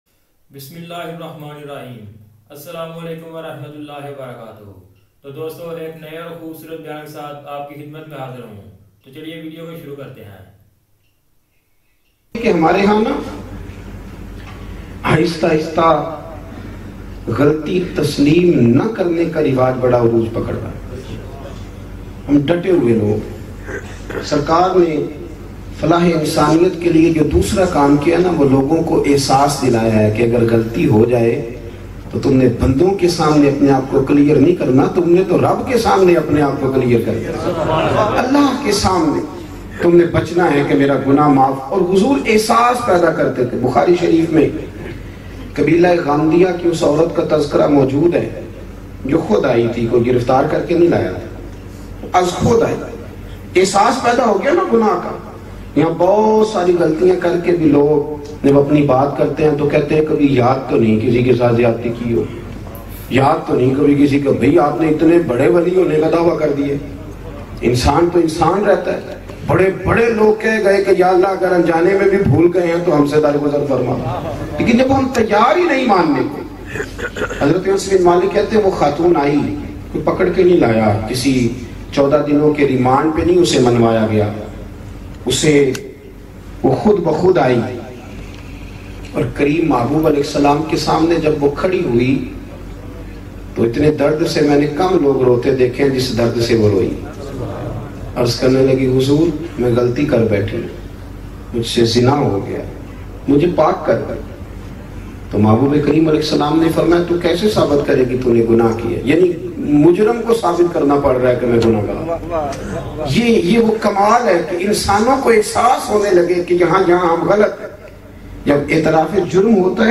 Bayan MP3 Download